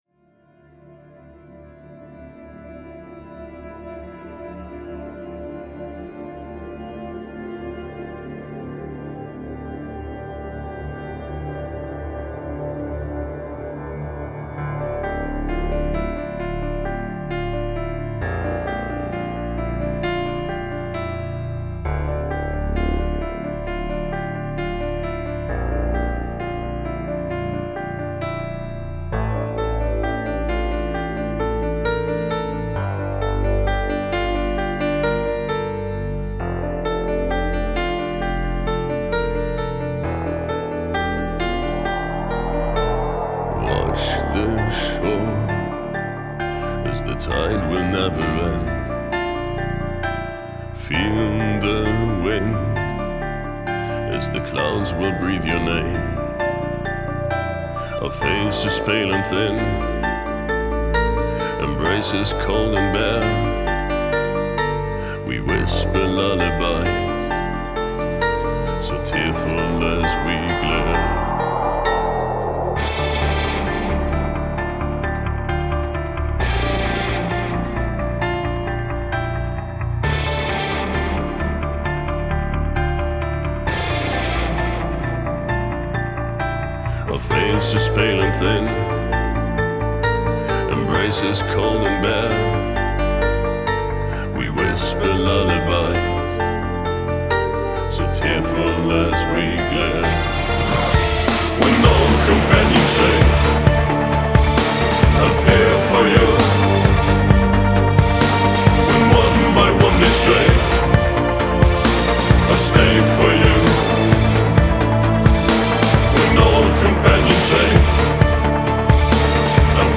*фоновая музыка